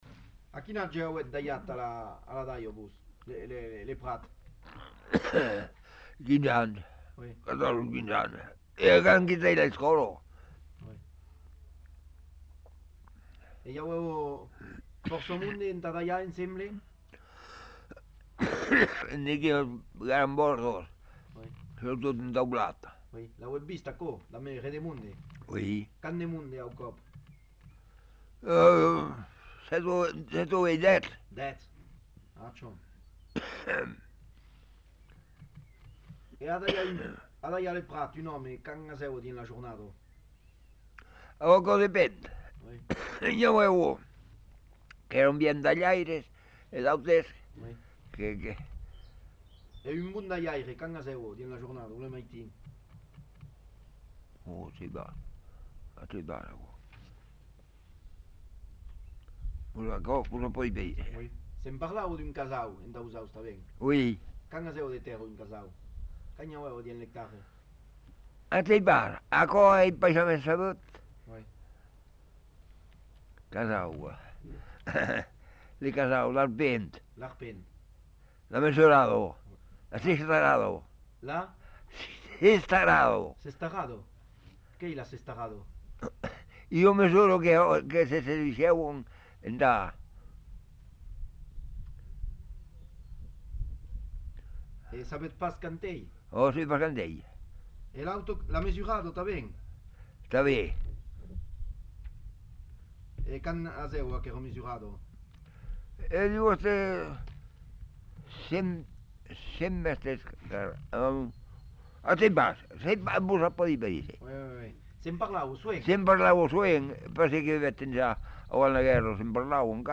Lieu : Montadet
Genre : témoignage thématique